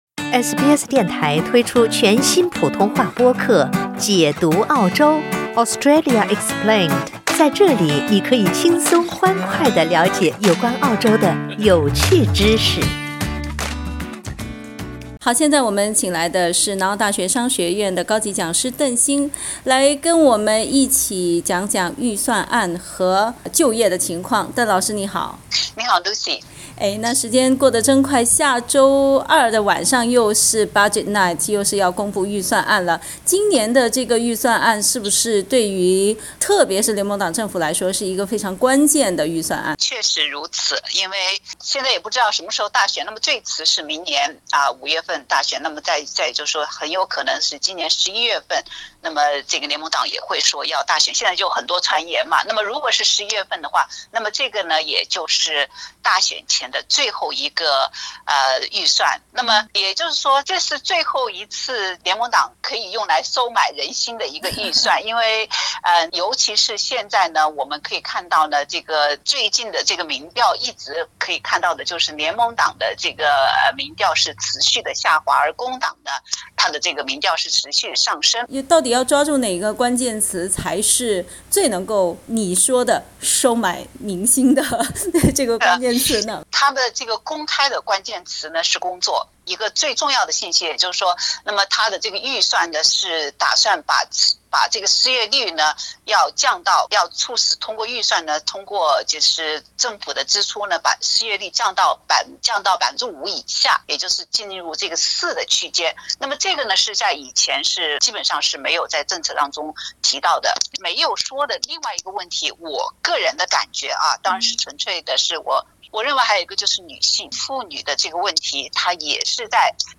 （本节目为嘉宾观点，不代表本台立场，请听采访。）